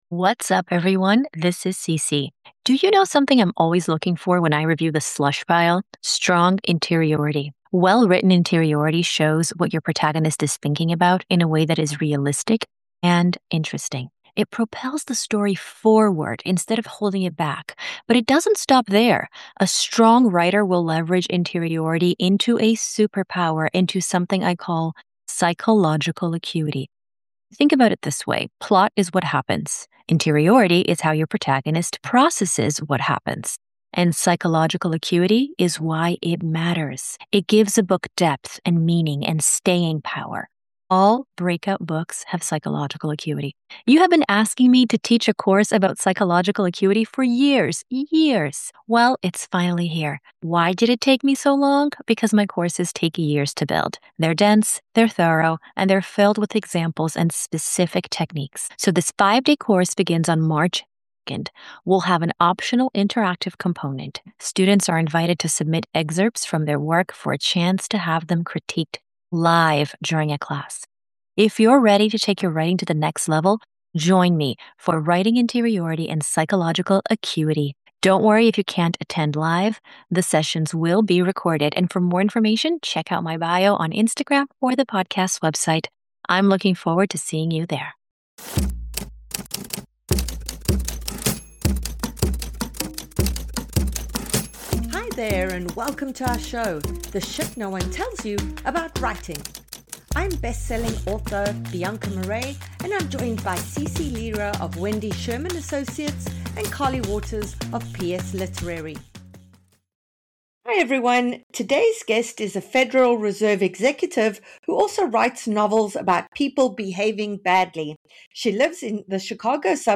In part one of this week’s double author interview